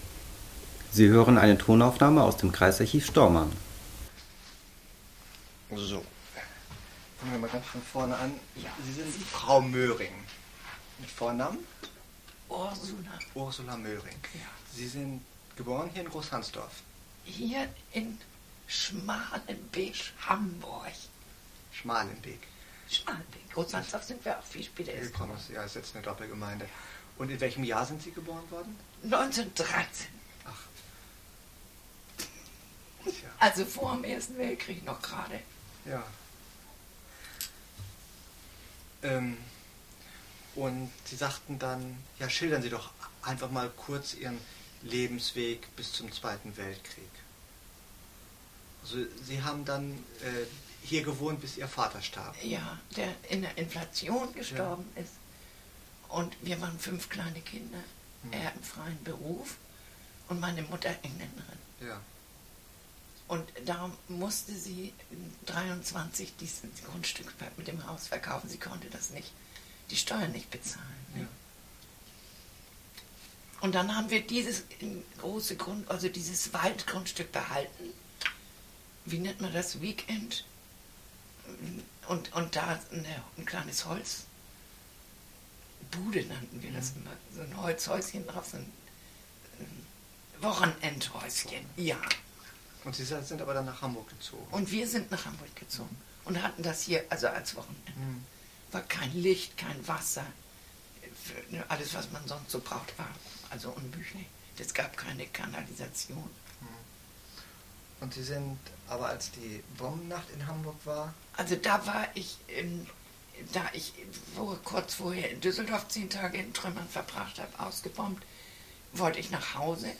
Tonkassette